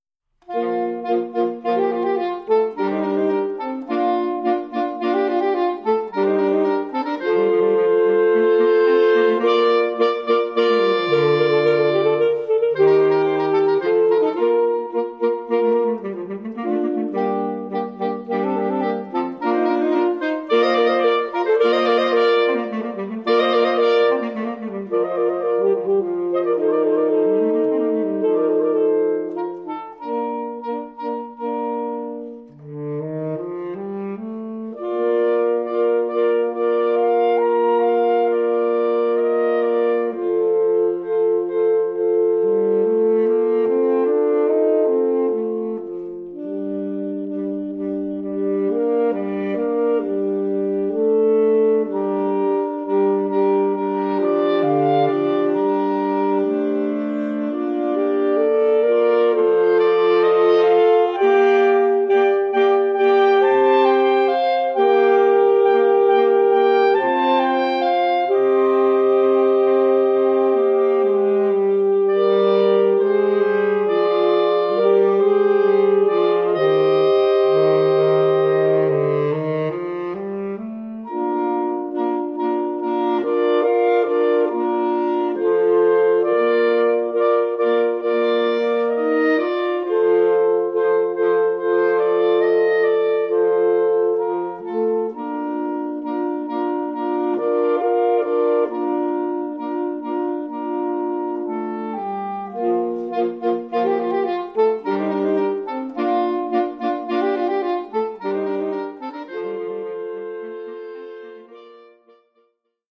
Formule instrumentale : Quatuor de saxophones
Oeuvre pour quatuor de saxophones.
une rondeur, un velouté, dont le saxophone a le secret.